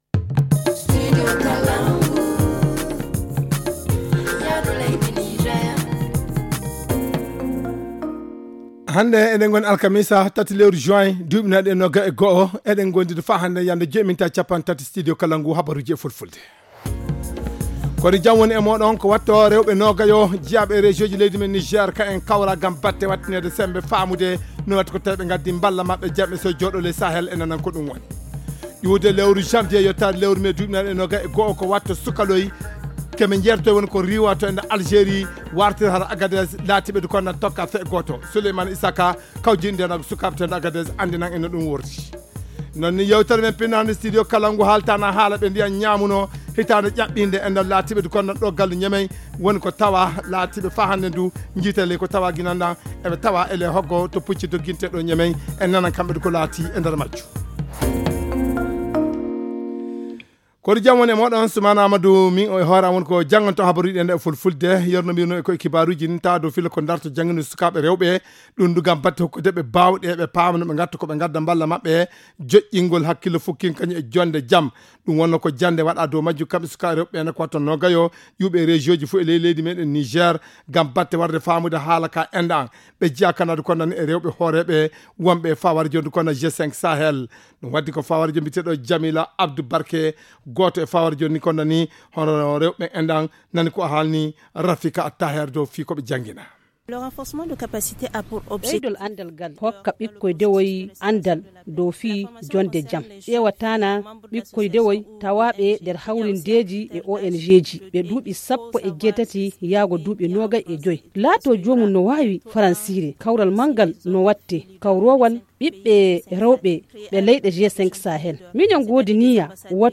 Le journal du 03 juin 2021 - Studio Kalangou - Au rythme du Niger